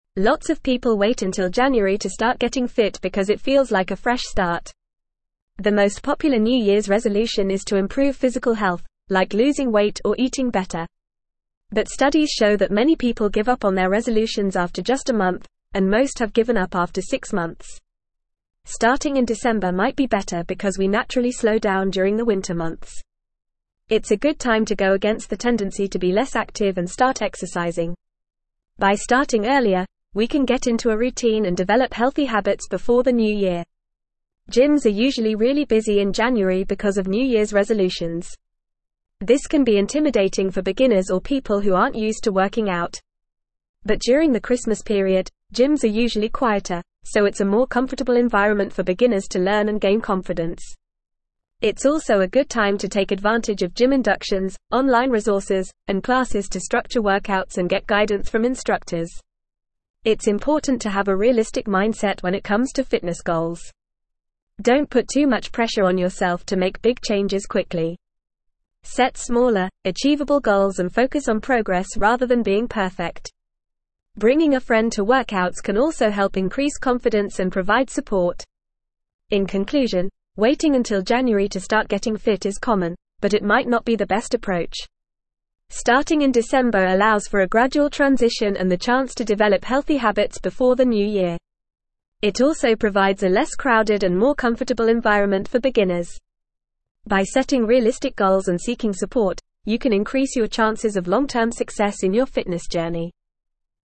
Fast
English-Newsroom-Upper-Intermediate-FAST-Reading-Starting-Your-Fitness-Journey-Why-December-is-Ideal.mp3